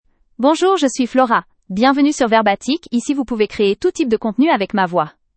Flora — Female French (Canada) AI Voice | TTS, Voice Cloning & Video | Verbatik AI
Flora is a female AI voice for French (Canada).
Voice sample
Listen to Flora's female French voice.
Flora delivers clear pronunciation with authentic Canada French intonation, making your content sound professionally produced.